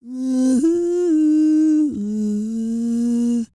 E-CROON 3034.wav